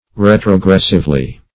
Retrogressively \Re`tro*gres"sive*ly\, adv.
retrogressively.mp3